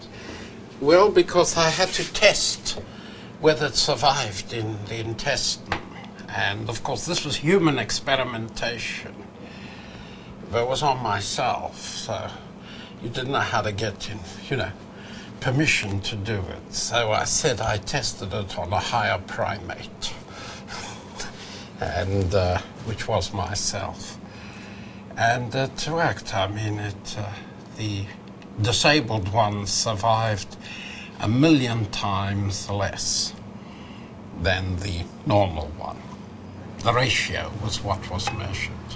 Interviewee: Sydney Brenner.